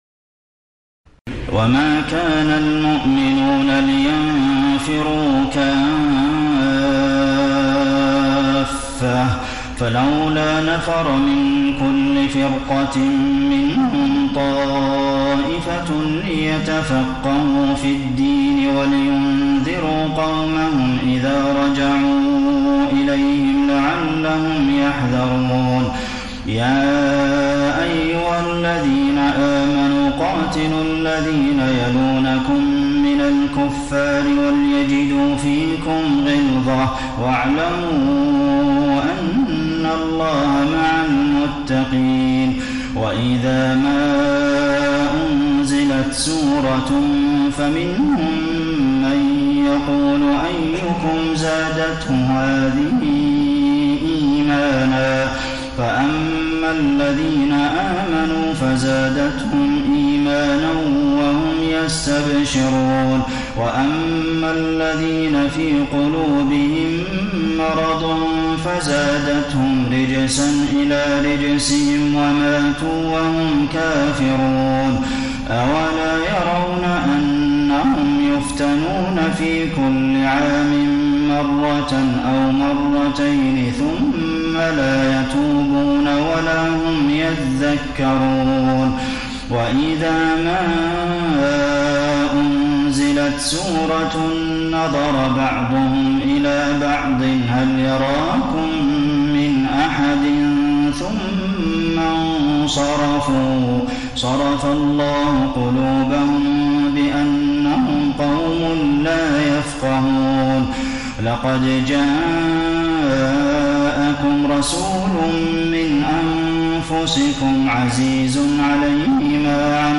تراويح الليلة الحادية عشر رمضان 1433هـ من سورتي التوبة (122-129) و يونس (1-70) Taraweeh 11 st night Ramadan 1433H from Surah At-Tawba and Yunus > تراويح الحرم النبوي عام 1433 🕌 > التراويح - تلاوات الحرمين